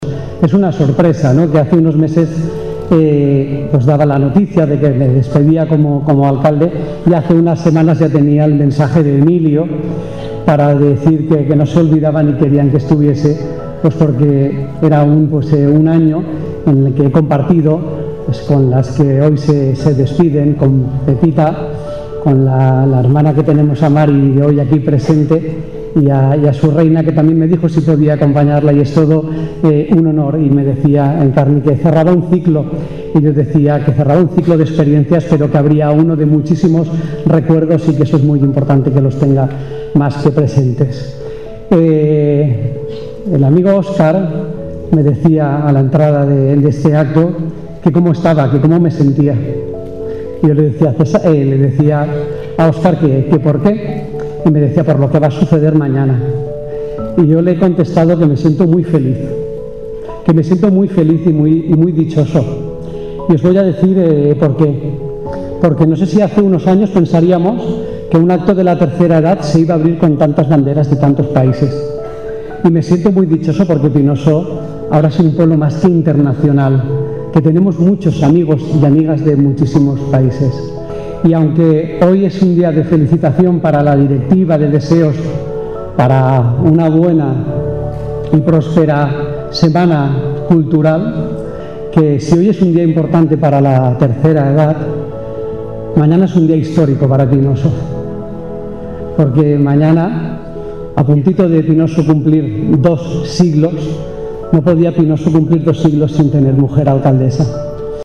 La 33ª edición de la Semana Cultural de la Asociación de Jubilados y Pensionistas “11 de septiembre” de Pinoso arrancó este jueves en el auditorio municipal.
El Diputado Nacional, Lázaro Azorín, también se dirigió a los presentes, alabando el esfuerzo del colectivo en la organización de estos actos.